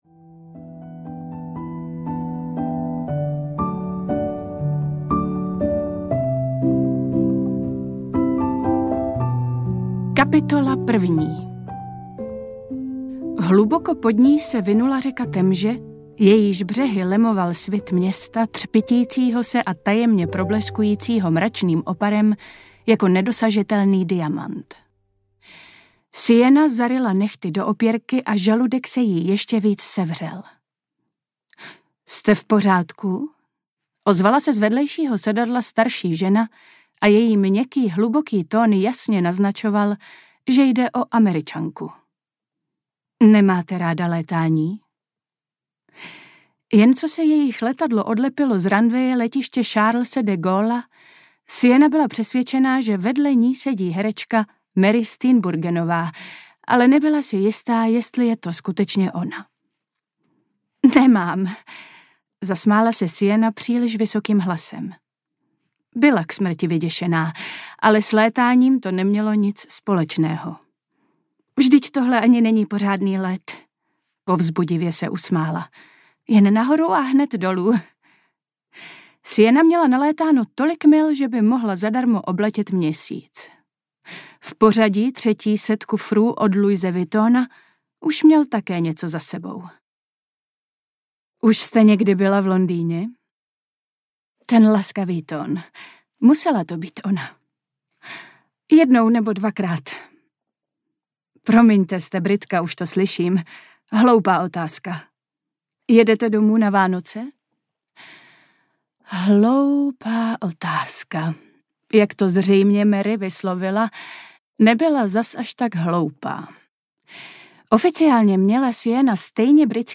S láskou z Paříže audiokniha
Ukázka z knihy
• InterpretAnna Fixová